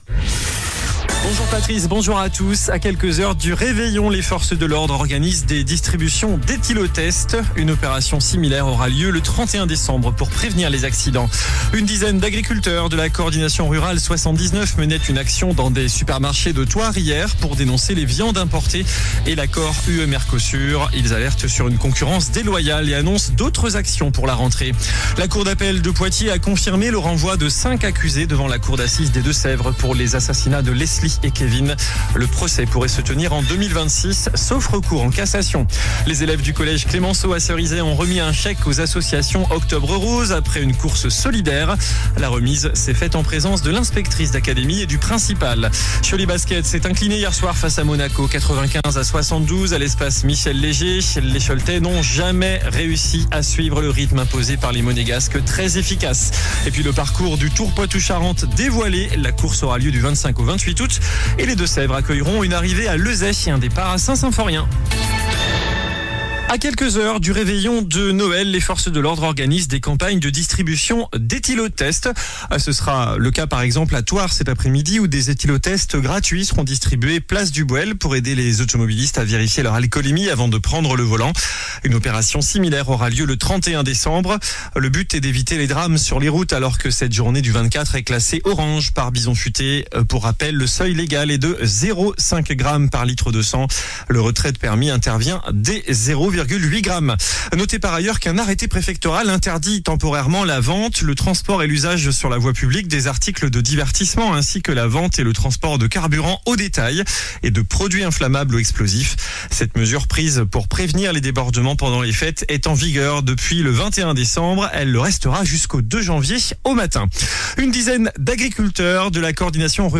JOURNAL DU MERCREDI 24 DECEMBRE ( MIDI )